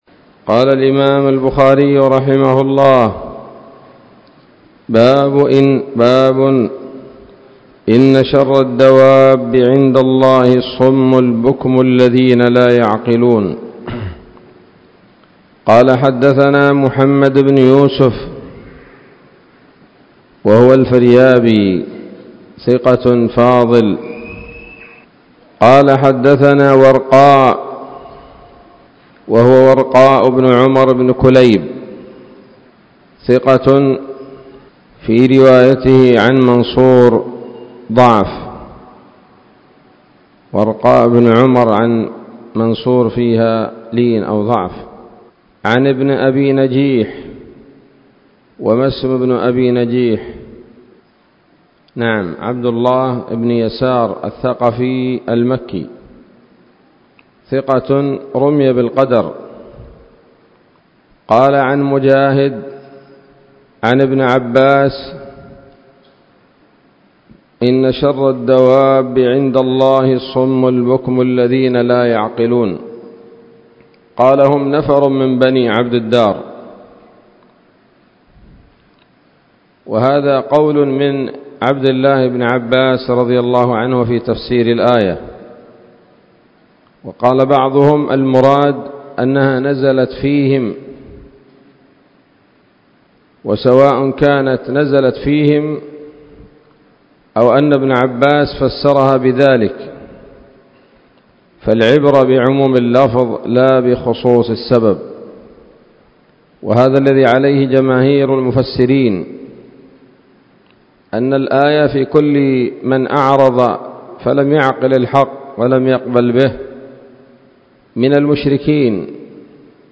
الدرس الثاني عشر بعد المائة من كتاب التفسير من صحيح الإمام البخاري